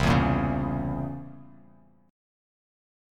C7b9 Chord
Listen to C7b9 strummed